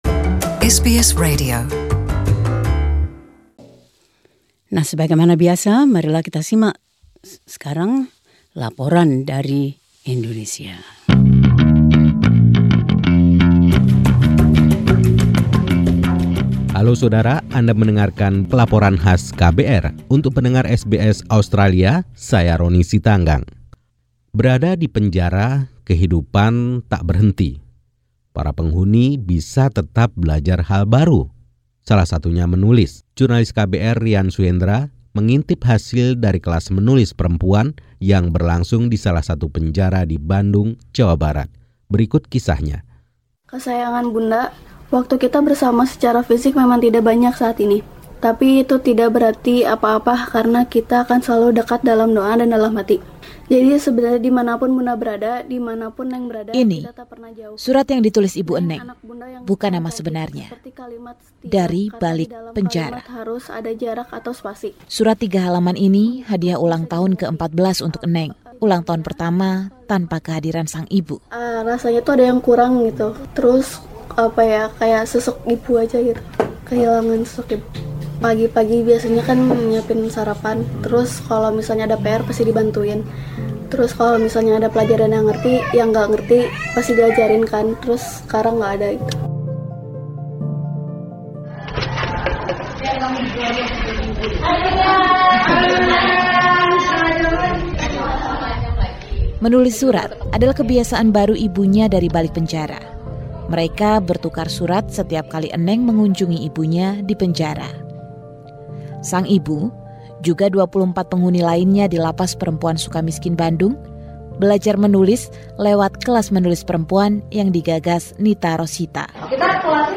Tim KBR 68H melaporkan program yang mengubah kehidupan itu.